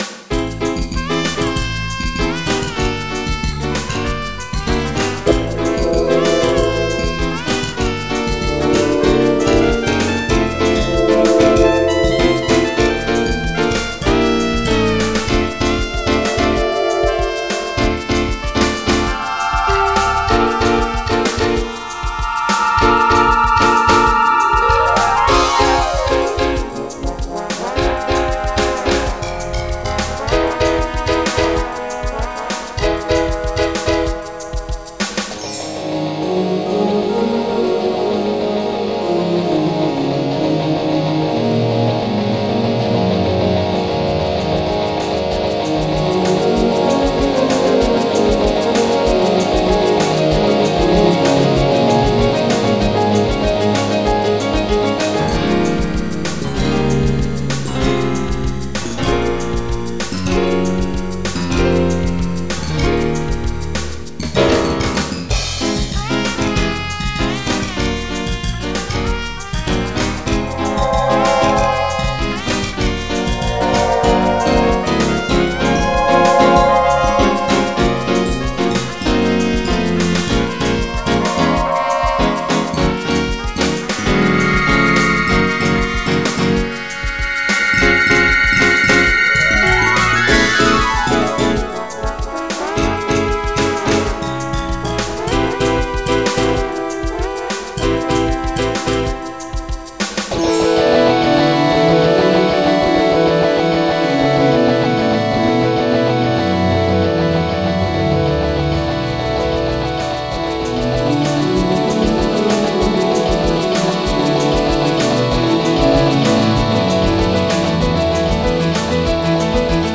Jazz
MIDI Music File